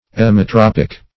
Emmetropic \Em`me*trop"ic\, a.